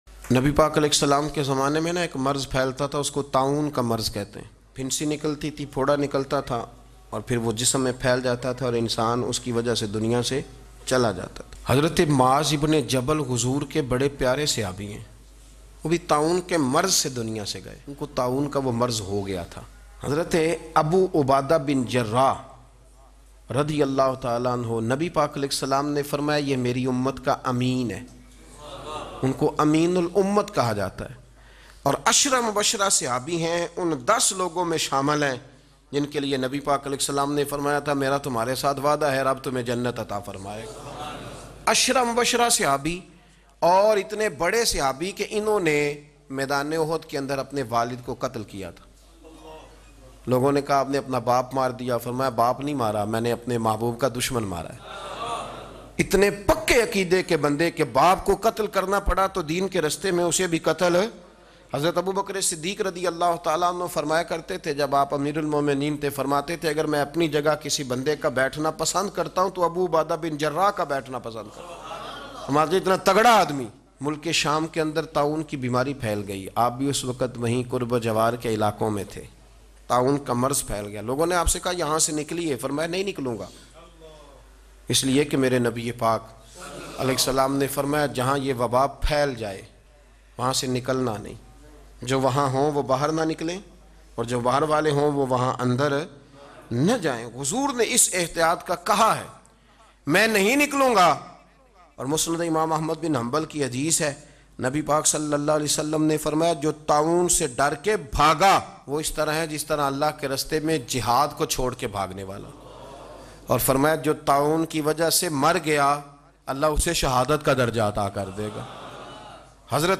Waba kay Dor Mein Hukam e Nabvi Aor Amel e Sahaba Bayan MP3 Download in best audio quality.